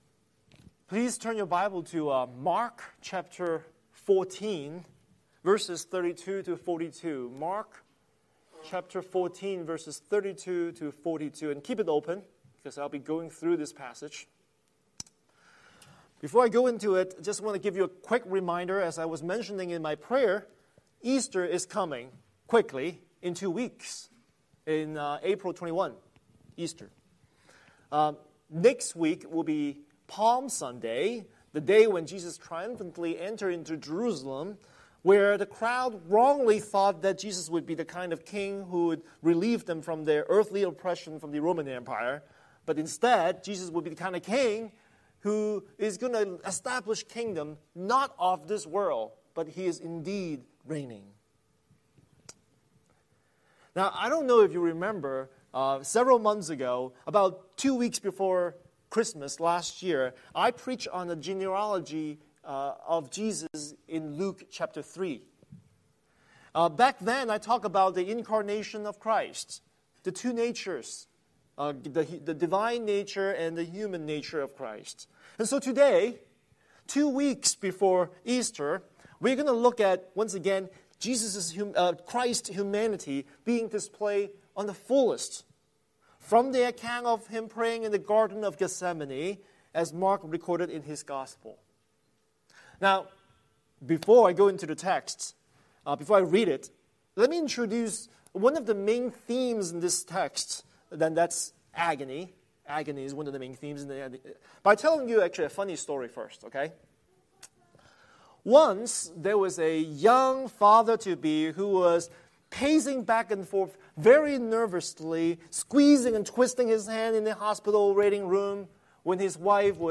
Scripture: Mark 14:32-42 Series: Sunday Sermon